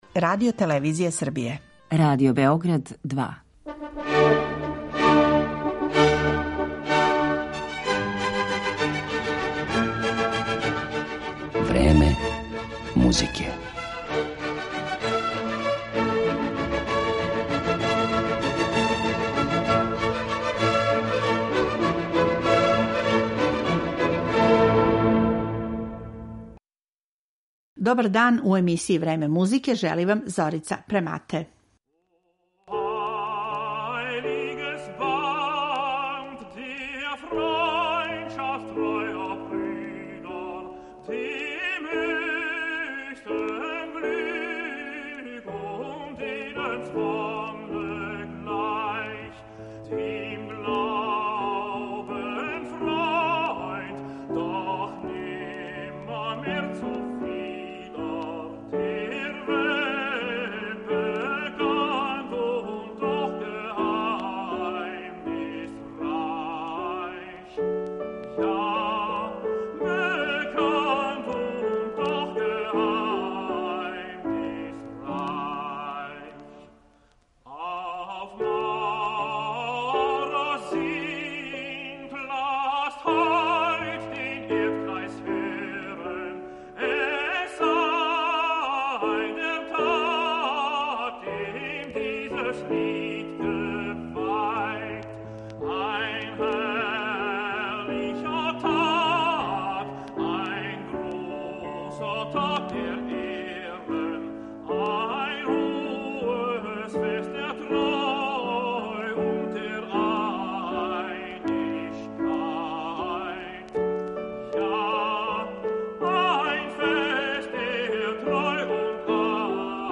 Почев од 1785. године компоновао је углавном песме уз камерни ансамбл или кантате са темама и стиховима који су одговарали масонским обредима, па ћемо емитовати управо избор из овог сегмента Моцартовог опуса.